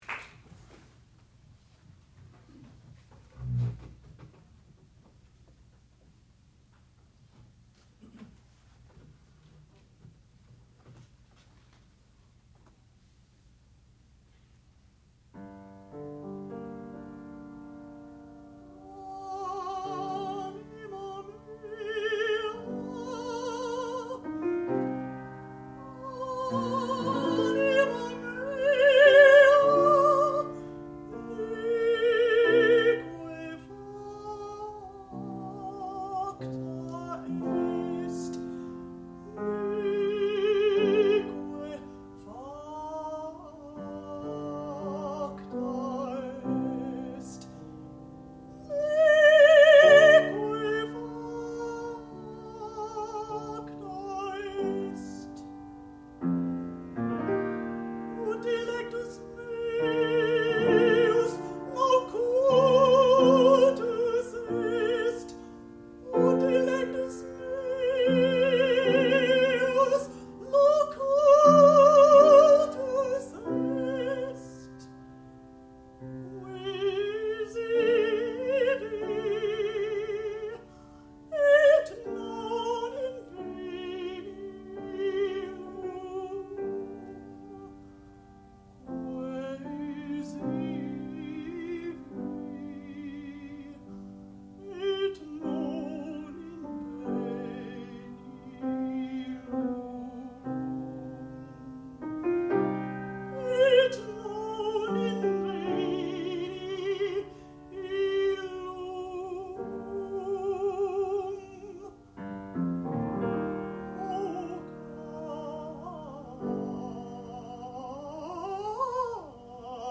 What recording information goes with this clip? Master's Degree Recital